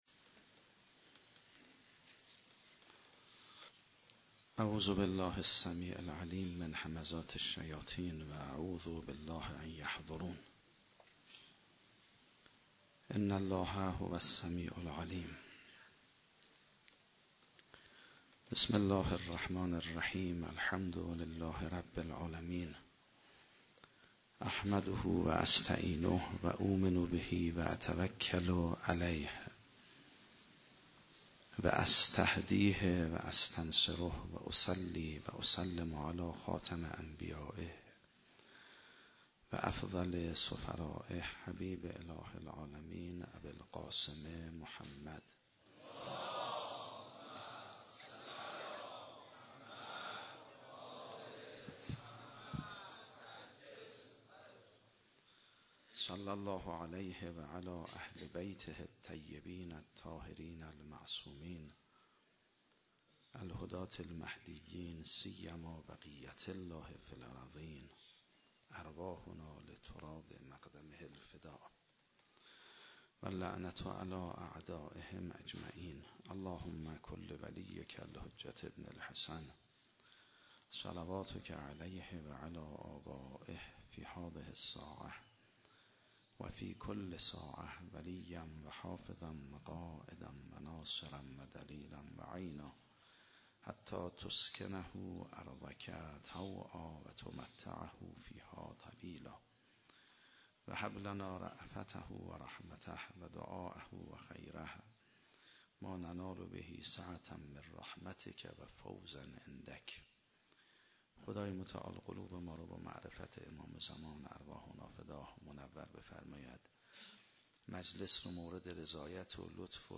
روز عاشورا محرم 96 - حسینیه حضرت زهرا - سخنرانی